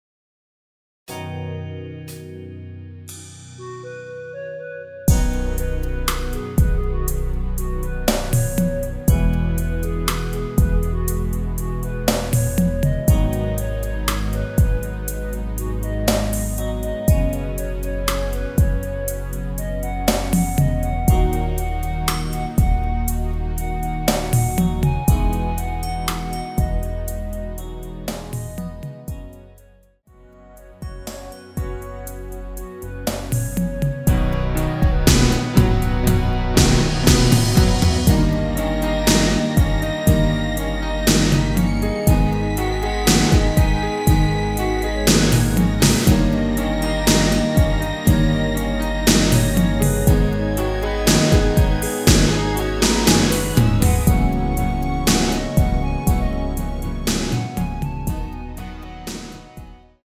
전주 없이 시작 하는곡이라 전주 1마디 만들어 놓았습니다.(미리듣기 확인)
원키에서(-2)내린 멜로디 포함된 MR입니다.(미리듣기 확인)
앞부분30초, 뒷부분30초씩 편집해서 올려 드리고 있습니다.
중간에 음이 끈어지고 다시 나오는 이유는